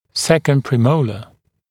[‘sekənd prɪ’məulə][‘сэкэнд при’моулэ]второй премоляр